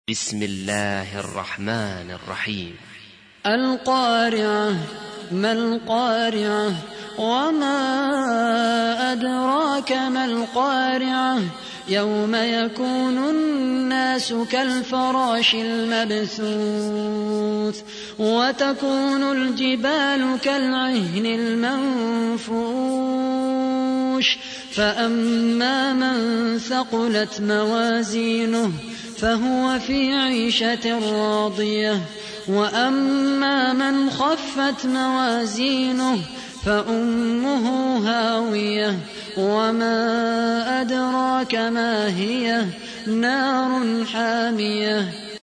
تحميل : 101. سورة القارعة / القارئ خالد القحطاني / القرآن الكريم / موقع يا حسين